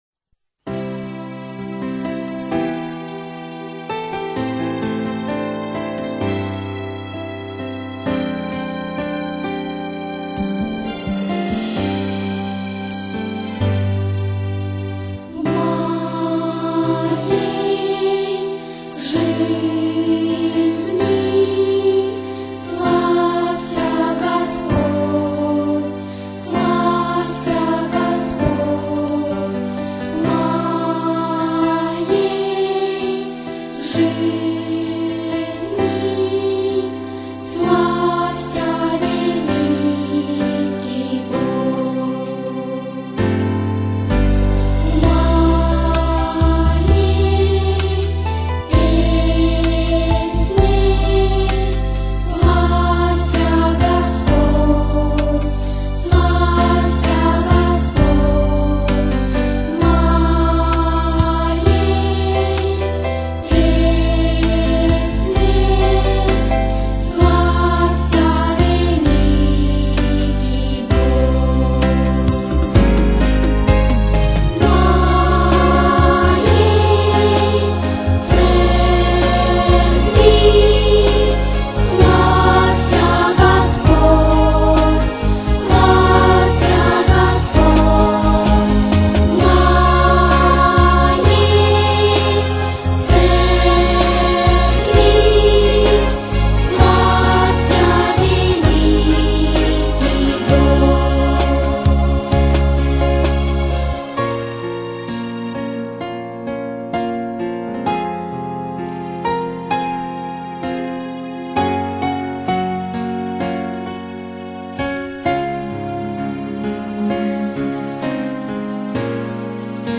on 2015-12-27 - Молитвенное пение
81083-V_moey_zhizni_-_Detskie_hristianskie_pesni.mp3